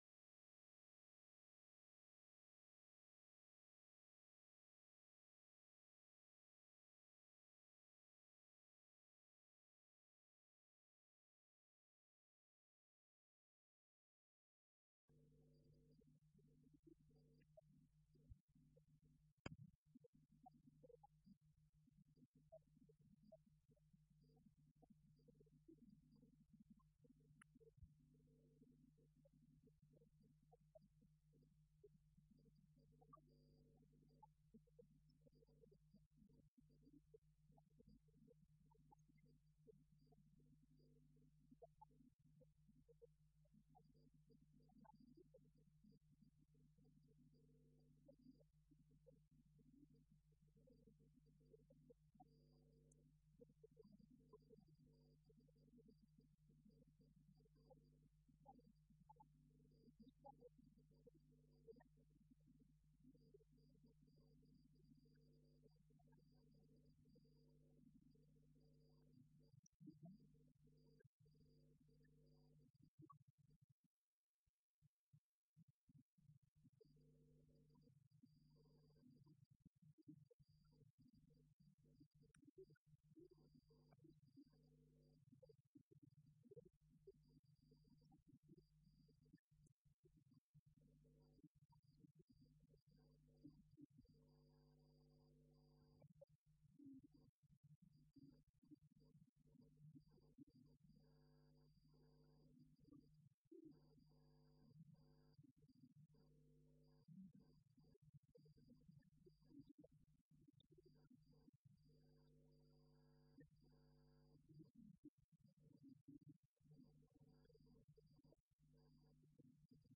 Colloque L’Islam et l’Occident à l’époque médiévale.